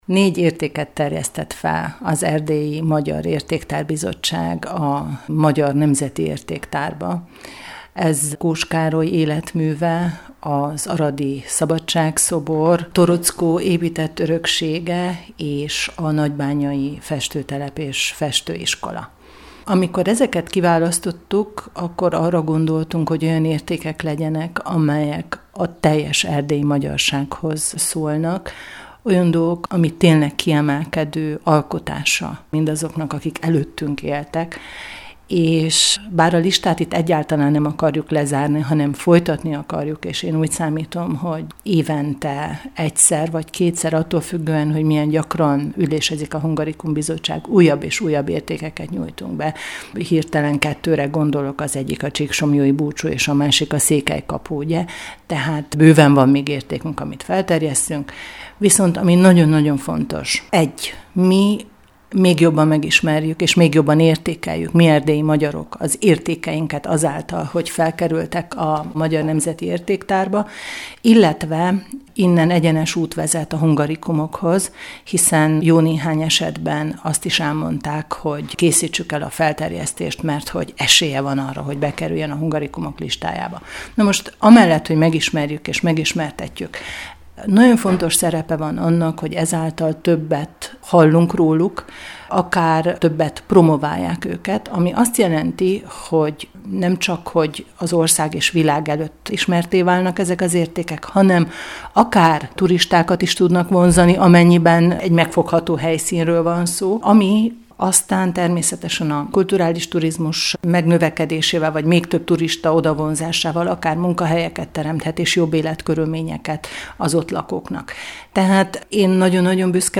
Döntött a Hungarikum Bizottság: kiemelkedő nemzeti érték lett Kós Károly életműve, az aradi Szabadság-szobor, Torockó épített öröksége, valamint a nagybányai művésztelep és festőiskola. Hegedüs Csillát, az RMDSZ kultúráért felelős ügyvezető alelnökét kérdezték Kolozsvári Rádiós kollégáink.